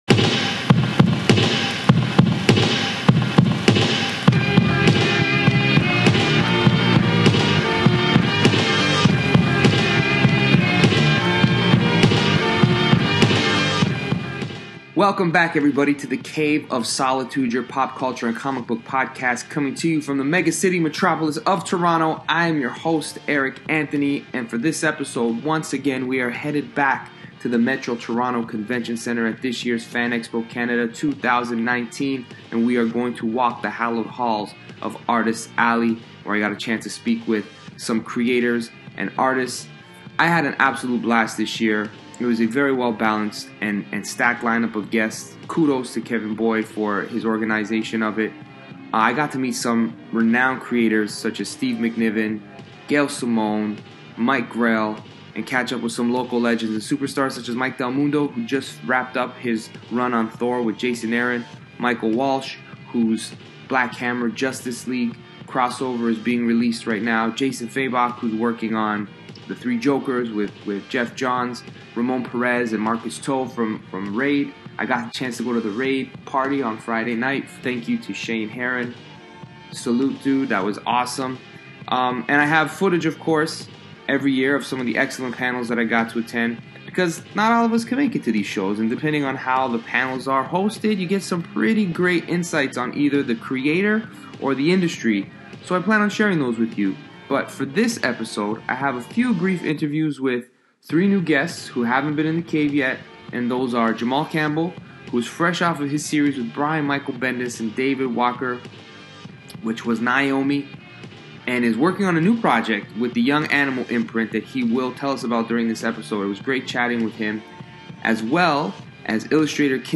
walks the hallowed halls of Artists alley at Fan Expo 2019
we get the chance to listen in on some conversations